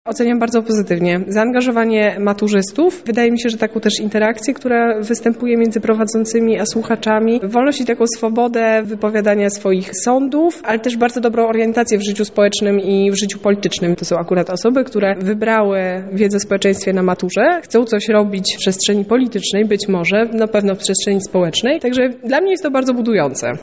Była tam również nasza reporterka.
Nocne Korki z WOSu relacja 1
Nocne-Korki-z-WOSu-relacja-2.mp3